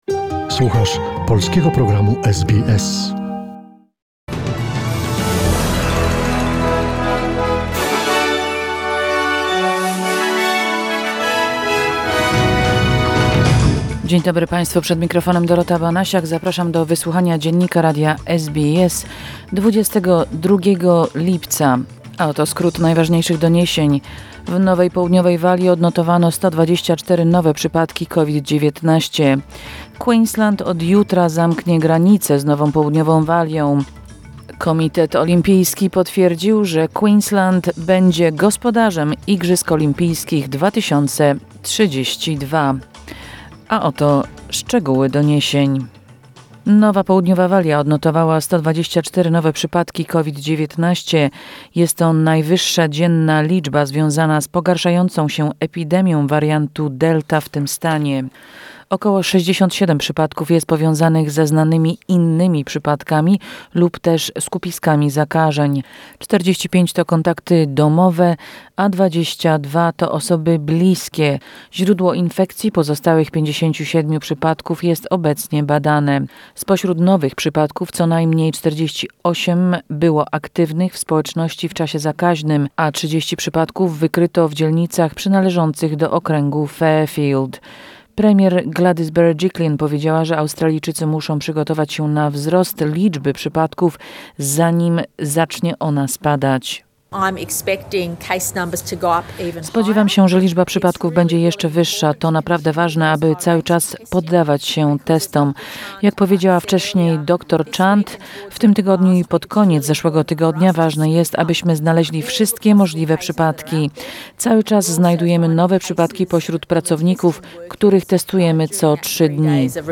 SBS News Flash in Polish, 22 July 2021